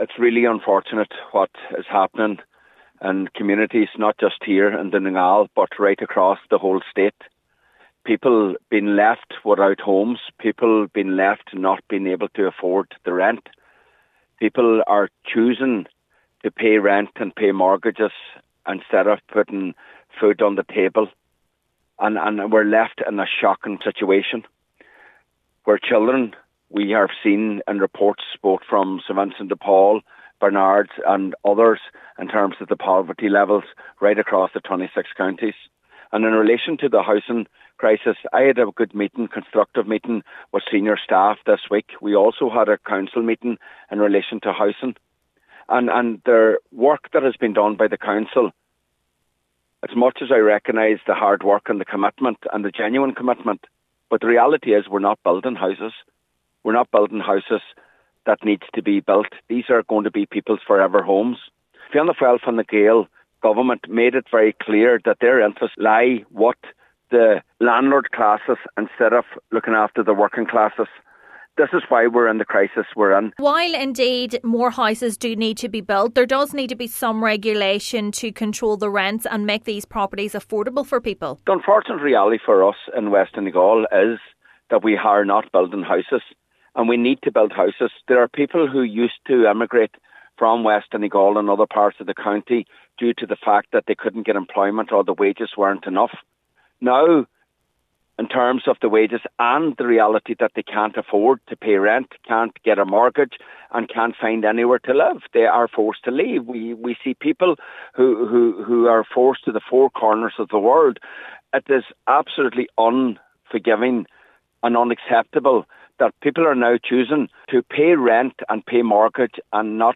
Councillor MacGiolla Easbuig says it is up to the Government however, to step in and provide assistance: